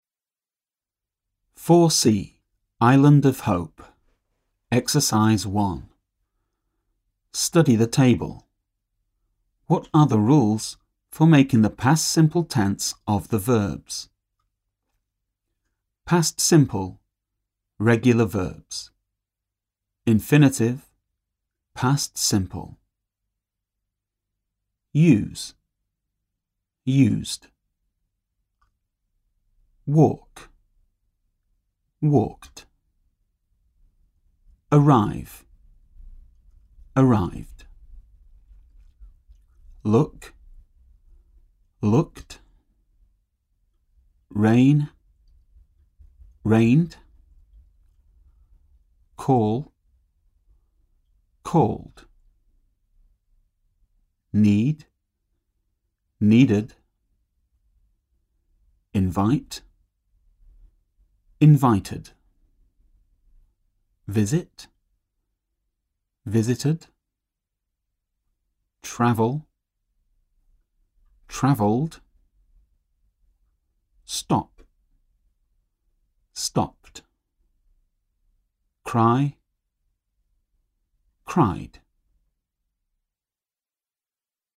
výslovnost procvičuj dle nahrávky v příloze
AJ 6_ poslech pravidelná slovesa p. 78 ex. 1.mp3